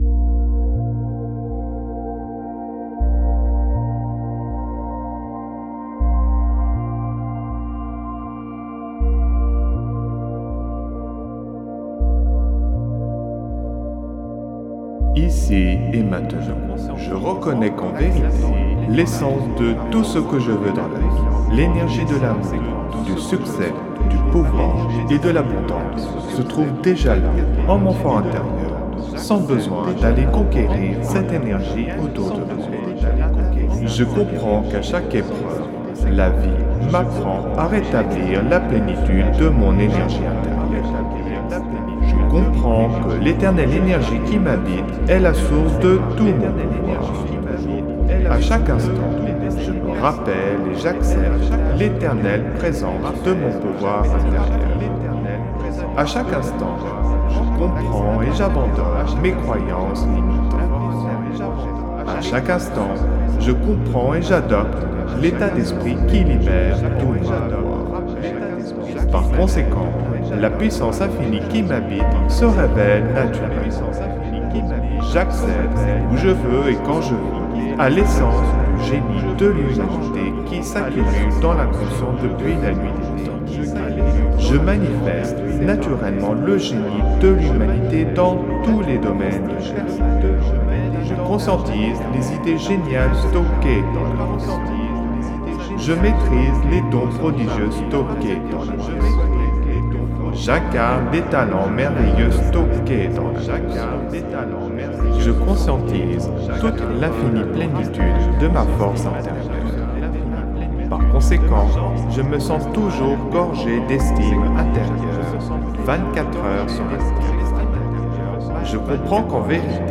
(Version ÉCHO-GUIDÉE)
LA QUINTESSENCE « tout-en-un » du développement personnel concentrée en un seul produit : Méditation, autosuggestion, message subliminal, musicothérapie, fréquences sacrées, son isochrone, auto hypnose, introspection, programmation neurolinguistique, philosophie, spiritualité, musique subliminale et psychologie.
Alliage ingénieux de sons et fréquences curatives, très bénéfiques pour le cerveau.
Puissant effet 3D subliminal écho-guidé.